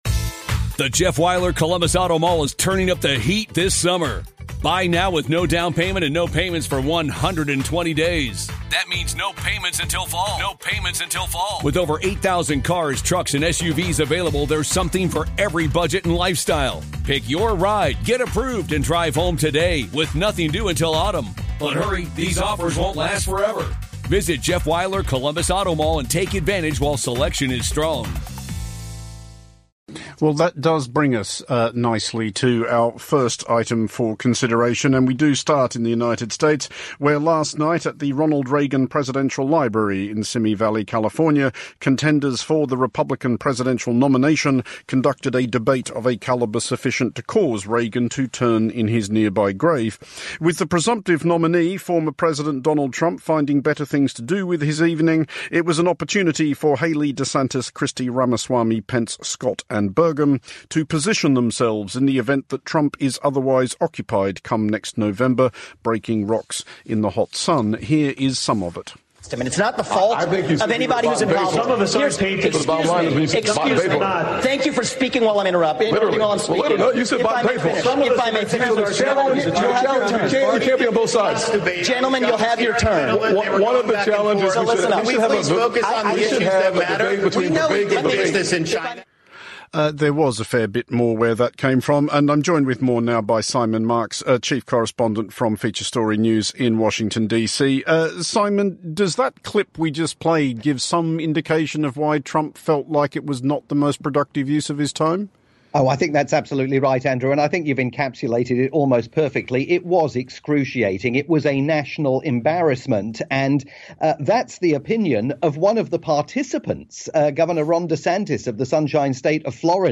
live report for Monocle Radio in the UK